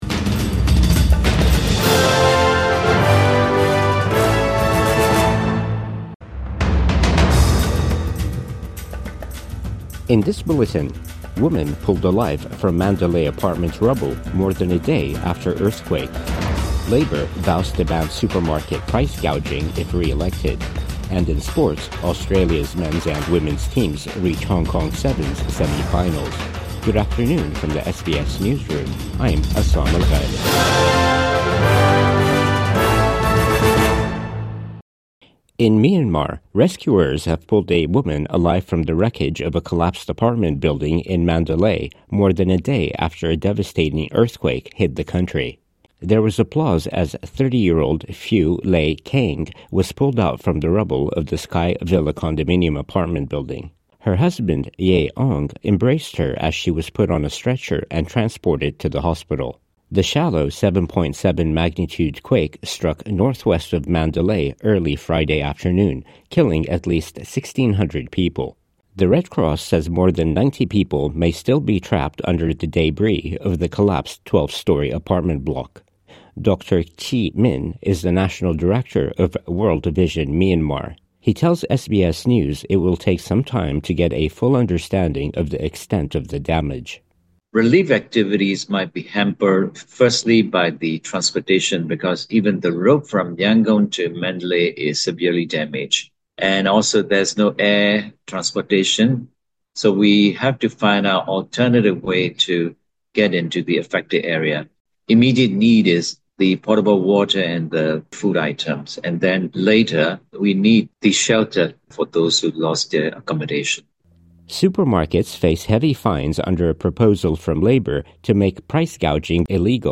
Midday News Bulletin 30 March 2025